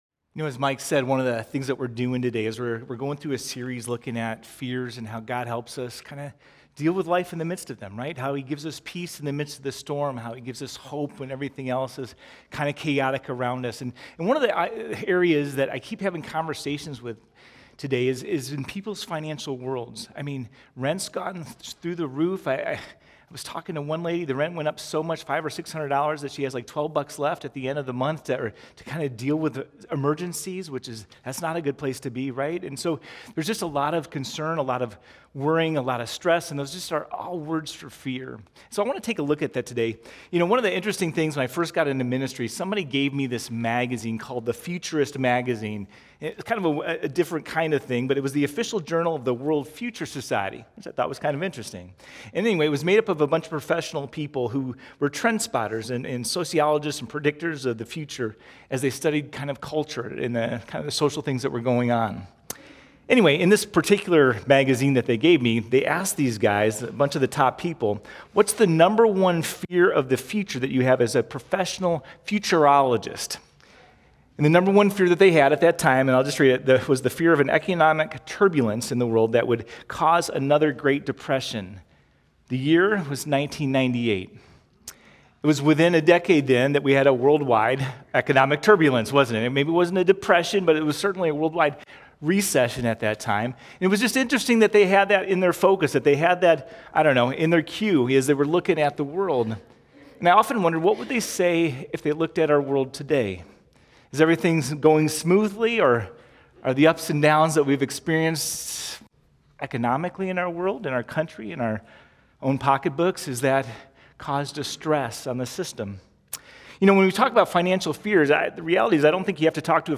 51Sermon.mp3